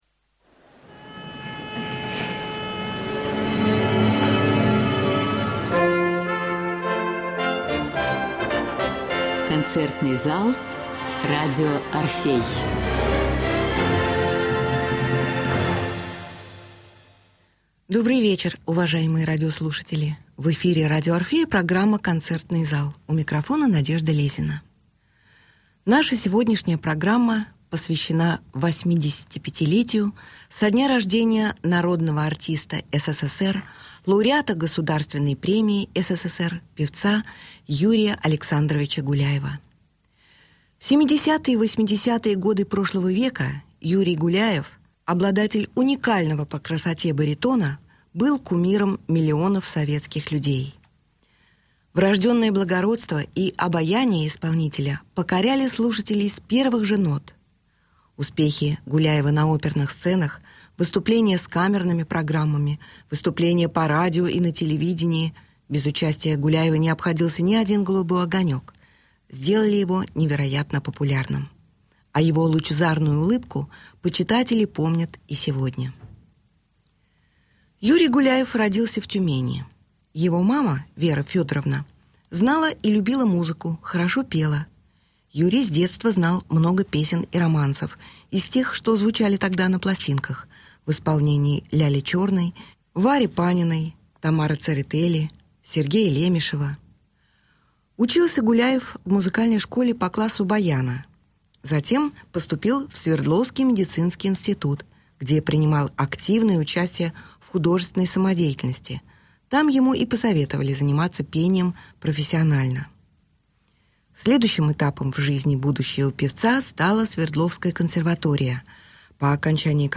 Арии из опер. Романсы